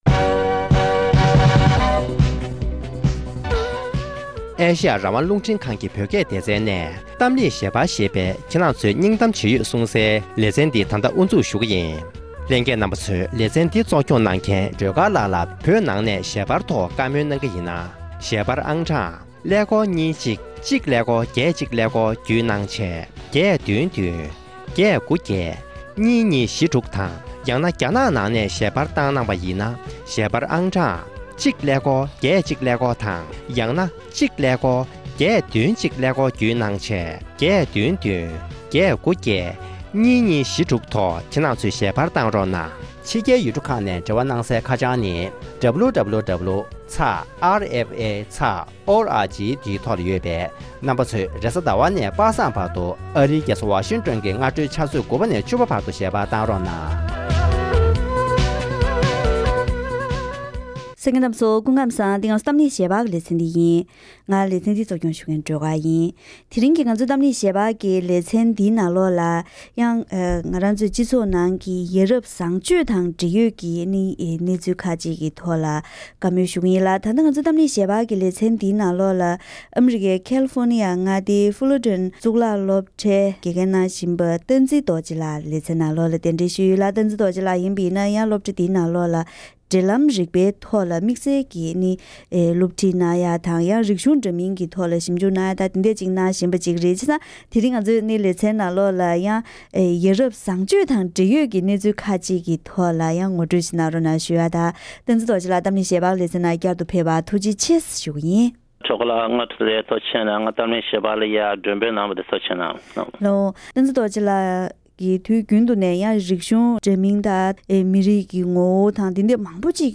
རྒྱུན་དུ་སྤྱི་ནོར་༧གོང་ས་༧སྐྱབས་མགོན་ཆེན་པོ་མཆོག་ནས་བཀའ་གནང་བའི་ཡ་རབས་བཟང་སྤྱོད་ཀྱི་སྐོར་འབྲེལ་ཡོད་དང་ལྷན་དུ་གླེང་མོལ།